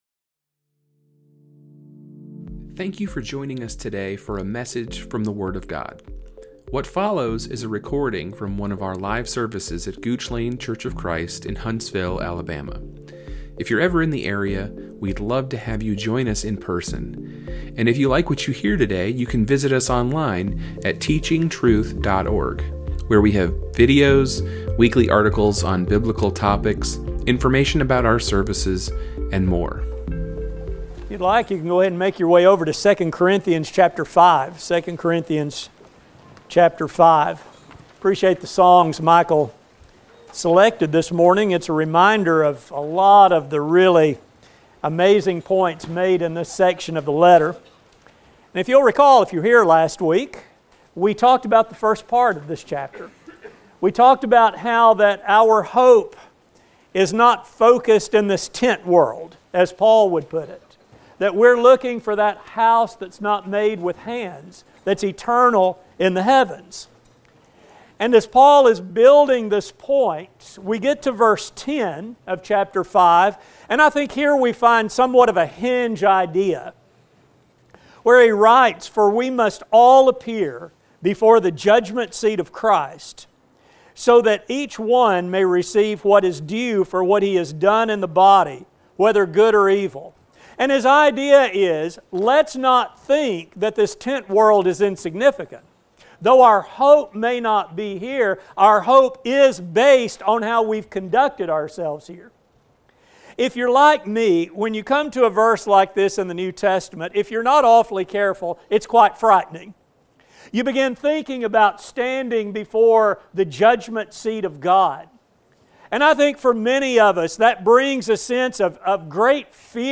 This sermon will explore the Apostle Paul’s message to the Corinthians on how we can not only remove fear from this judgment scene, but also eagerly anticipate its arrival. A sermon